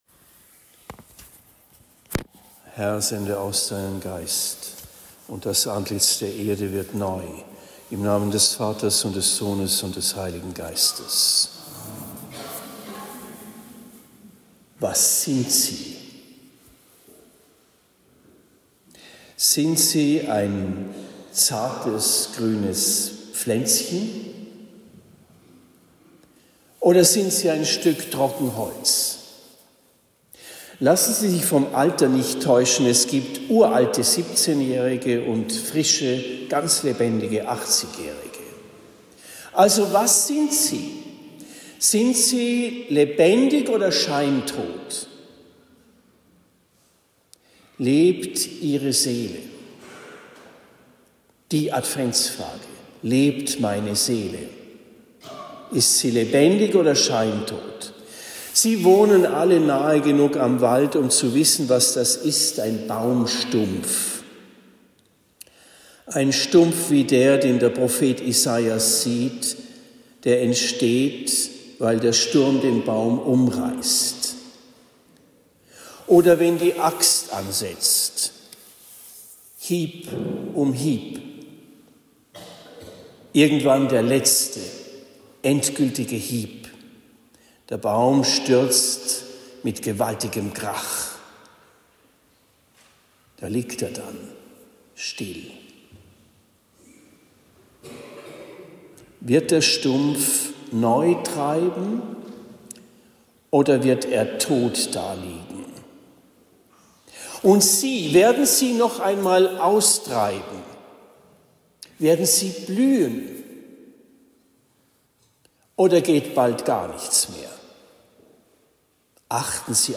Predigt in Esselbach am 07. Dezember 2025